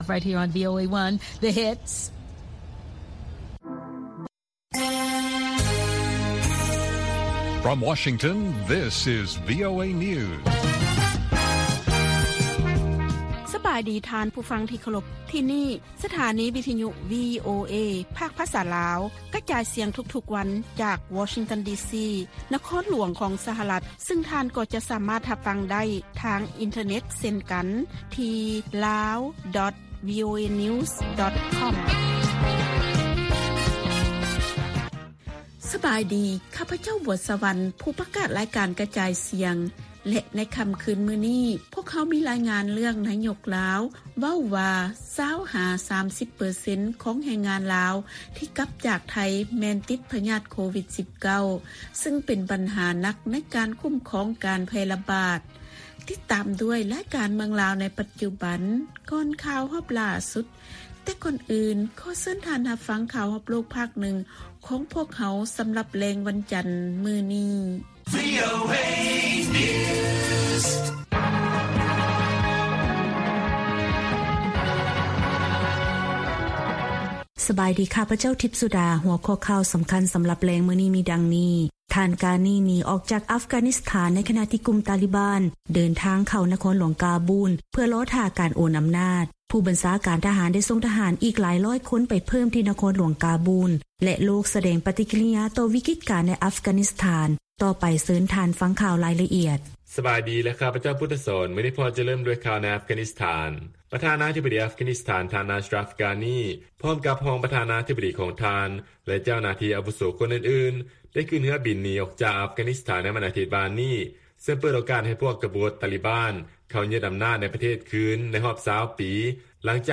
ວີໂອເອພາກພາສາລາວ ກະຈາຍສຽງທຸກໆວັນ. ຫົວຂໍ້ຂ່າວສໍາຄັນໃນມື້ນີ້ມີ: 1) ນາຍົກລາວ ກ່າວວ່າ 20 ຫາ 30 ເປີເຊັນຂອງພວກແຮງງານລາວທີ່ກັບຄືນມາບ້ານຈາກໄທ ຕິດເຊື້ອໂຄວິດ-19.